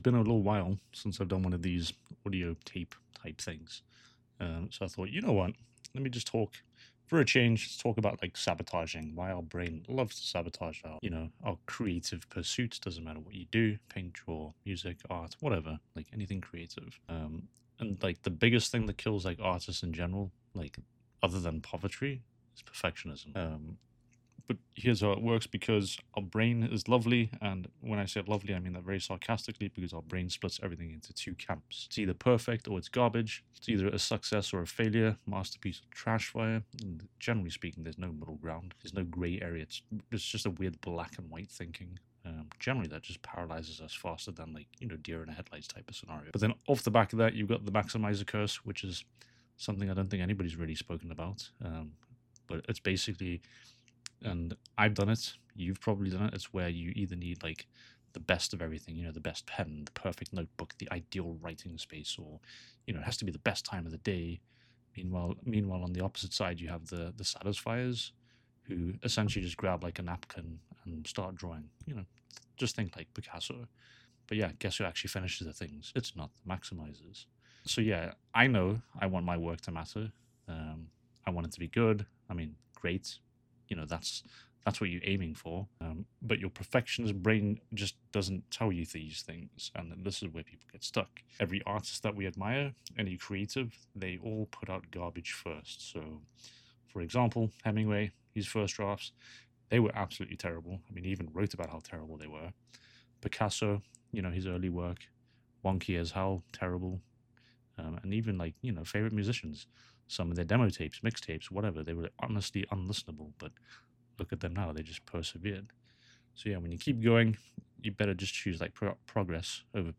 Some call it a podcast, some call it a random voice note.
Don’t worry It’s not the crappy $20 lavalier mic.
I take keywords and/or ideas and just riff on it.